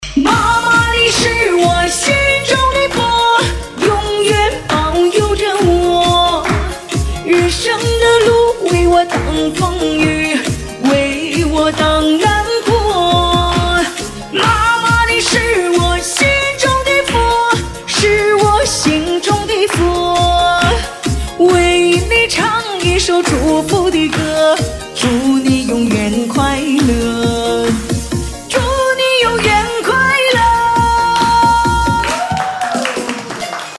Chinese music